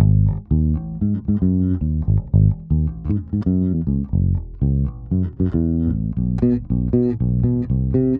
04 Bass PT4.wav